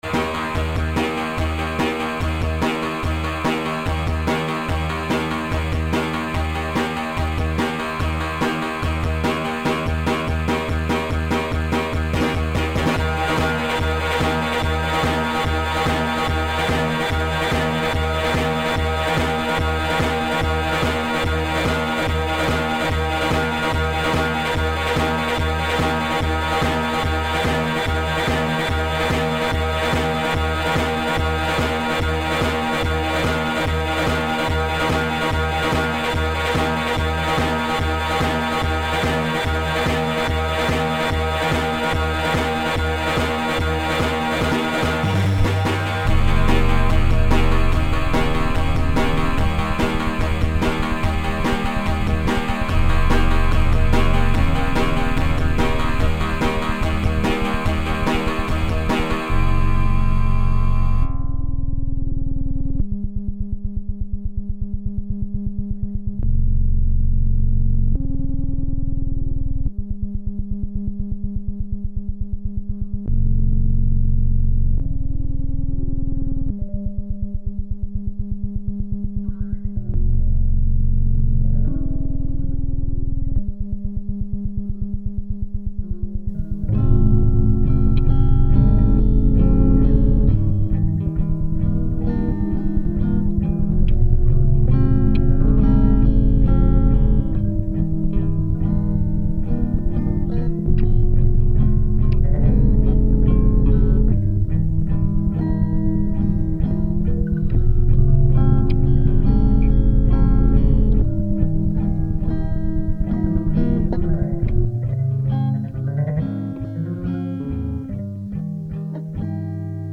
So much recording, cutting, pasting... and for what?